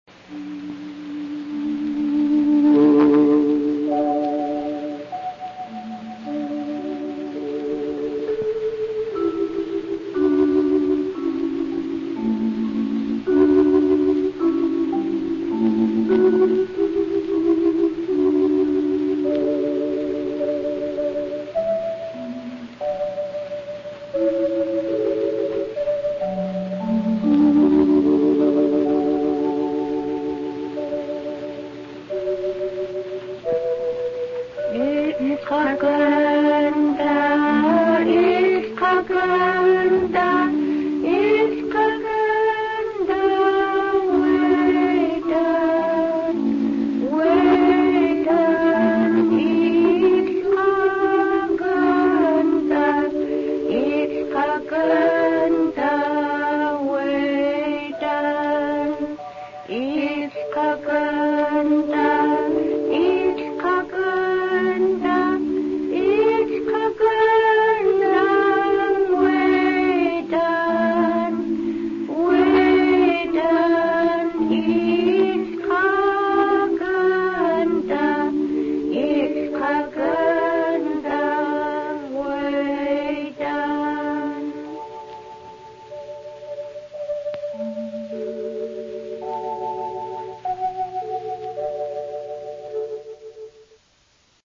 Here are six short gospel songs sung in Haida.
gospel-song-01.mp3